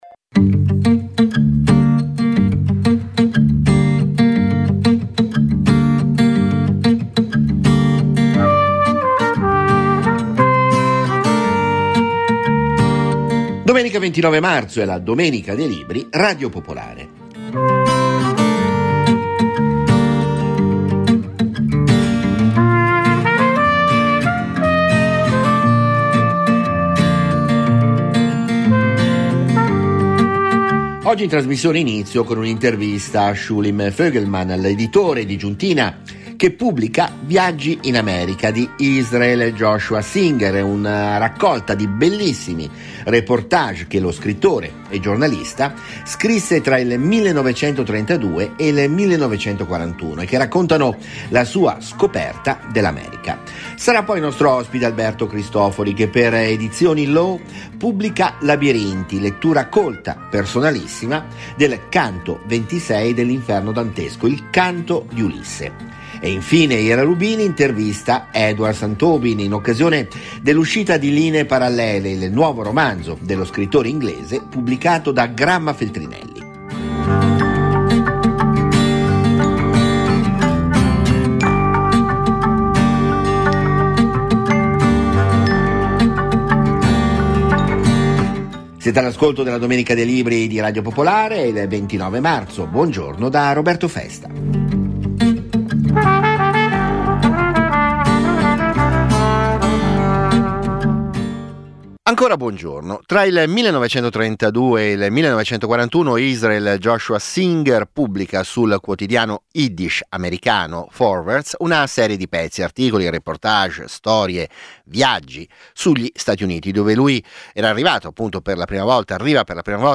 Ogni domenica, dalle 10.35 alle 11.30, interviste agli autori, approfondimenti, le novità del dibattito culturale, soprattutto la passione della lettura e delle idee.